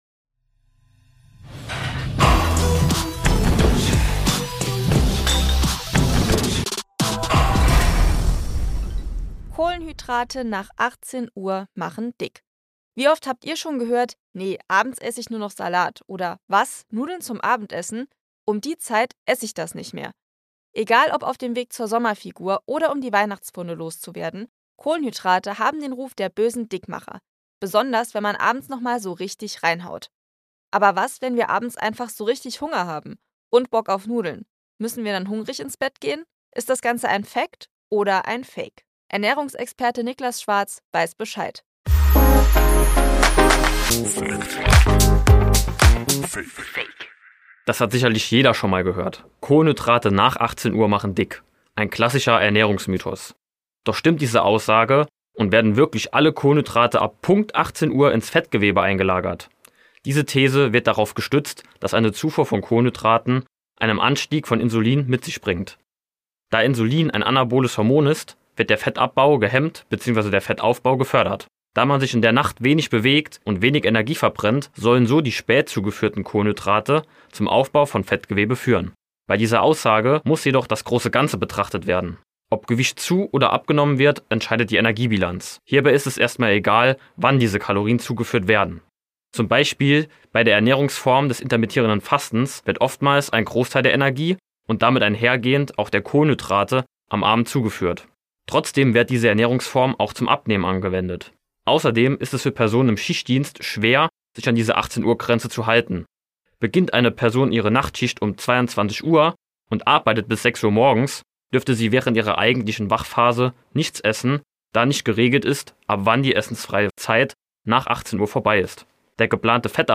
Zu Gast: Ernährungsexperte